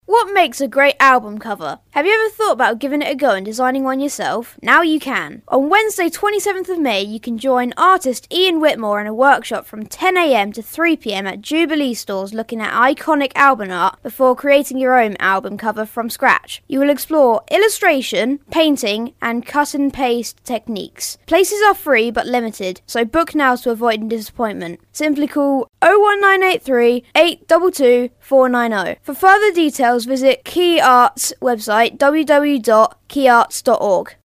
Quay Arts Promo Live On Vectis Radio